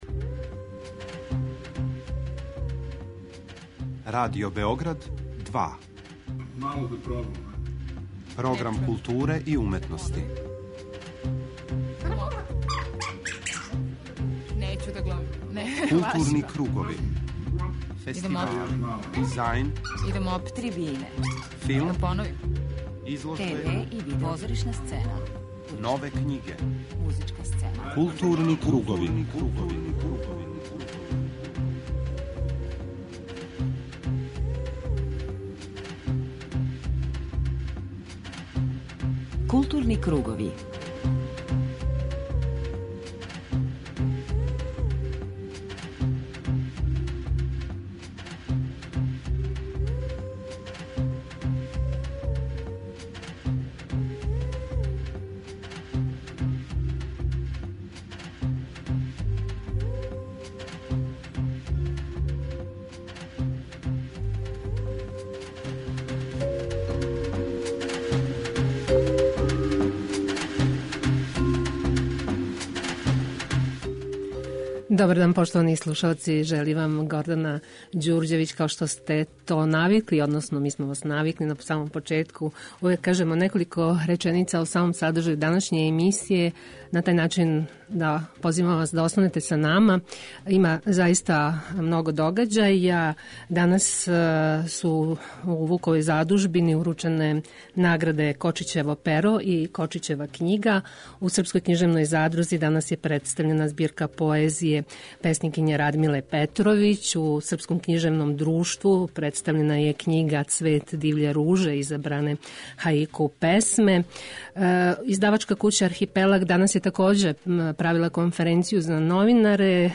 преузми : 40.24 MB Културни кругови Autor: Група аутора Централна културно-уметничка емисија Радио Београда 2.